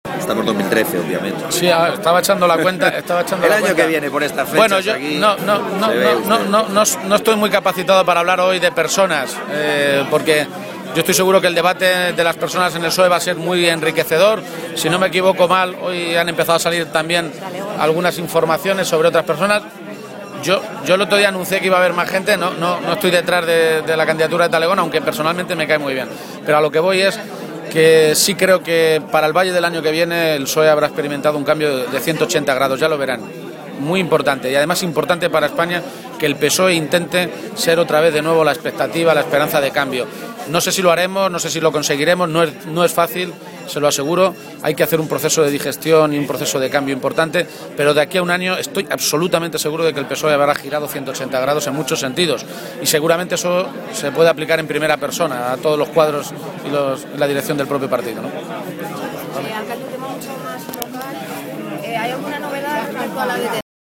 Page se pronunciaba así, esta mañana, en la tradicional Romería del Valle de Toledo, a preguntas de los medios de comunicación.
Cortes de audio de la rueda de prensa